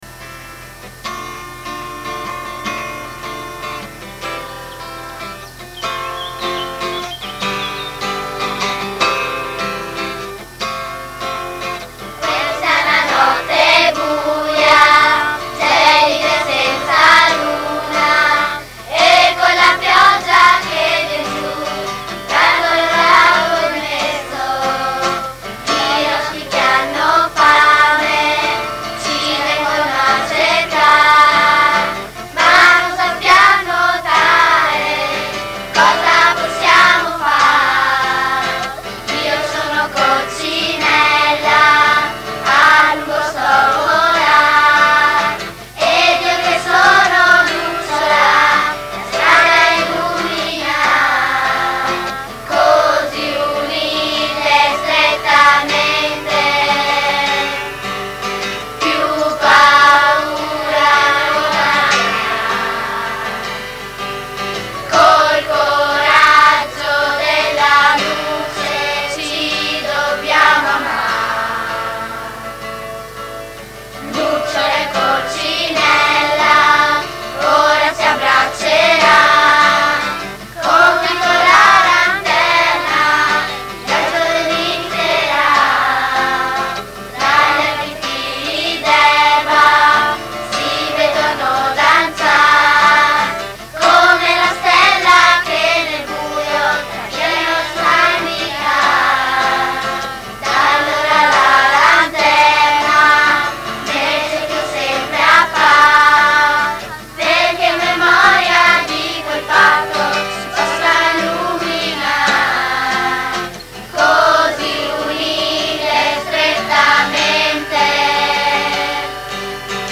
Cantata dal Cerchio dell�Arcobaleno, Reggio Emilia 3